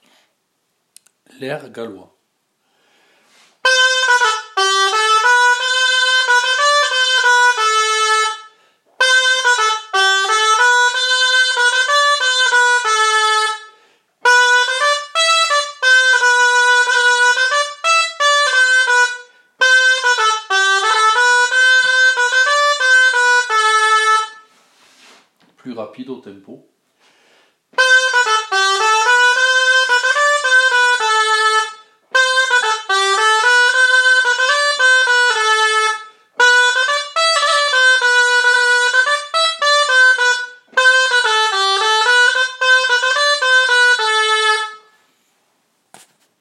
Air gallois
Air-gallois.m4a